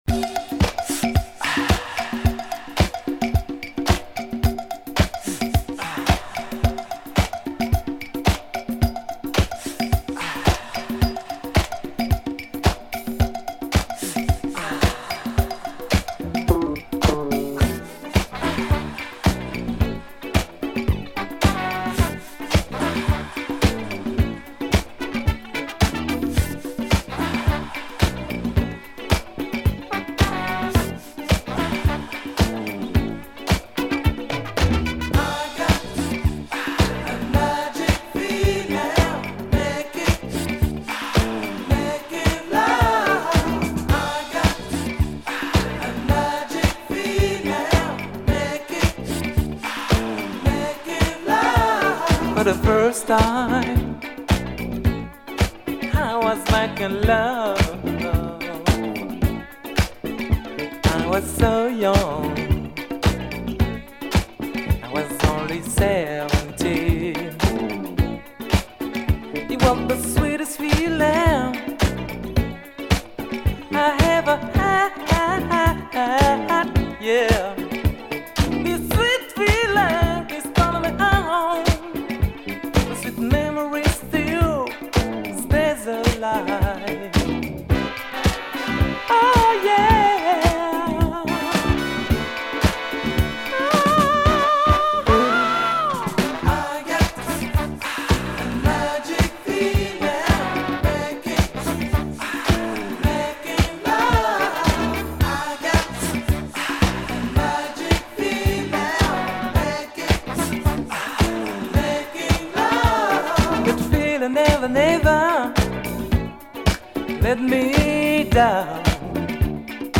afro boogie funk